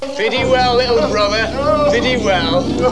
Una raccolta di clip audio dal film